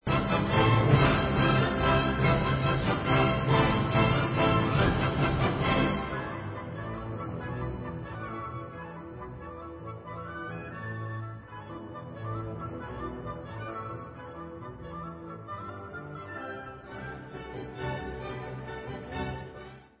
C dur (Presto) /Furiant